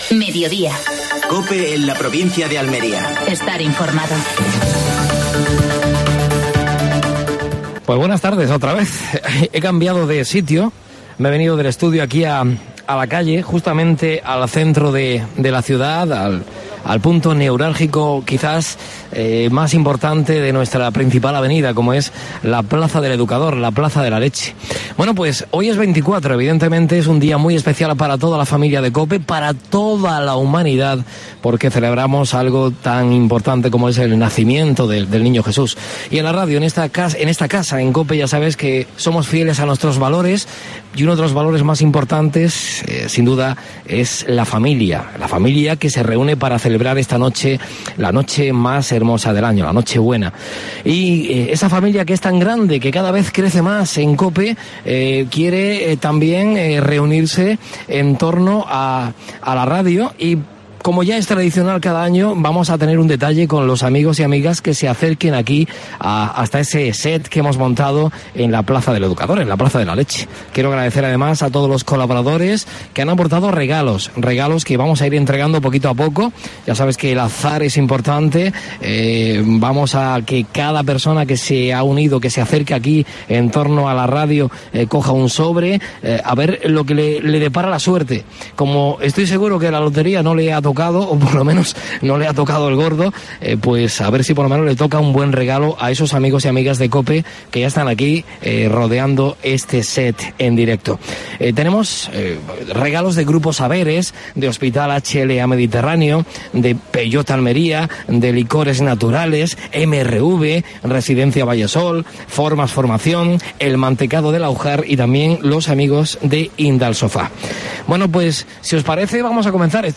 AUDIO: Programa especial desde la Plaza del Educador de COPE Almería repartiendo regalos entre los oyentes.